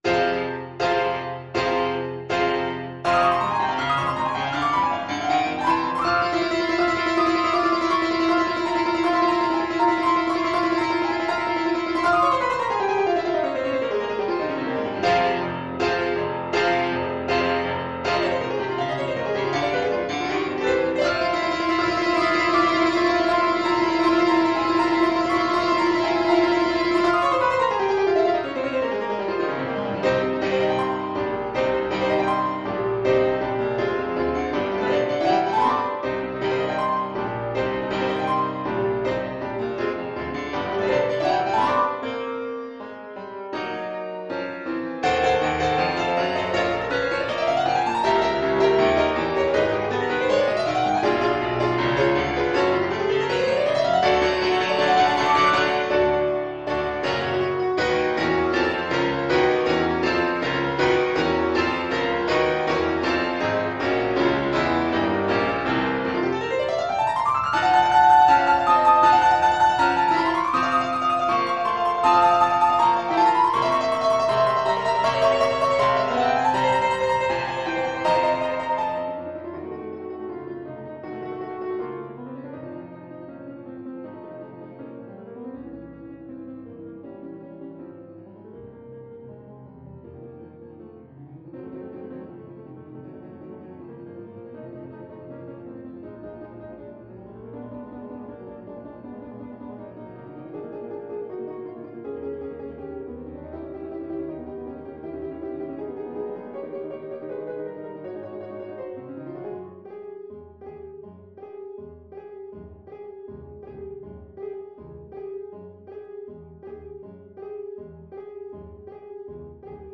Play (or use space bar on your keyboard) Pause Music Playalong - Piano Accompaniment Playalong Band Accompaniment not yet available reset tempo print settings full screen
Allegro agitato (=80) (View more music marked Allegro)
F minor (Sounding Pitch) D minor (Alto Saxophone in Eb) (View more F minor Music for Saxophone )
Classical (View more Classical Saxophone Music)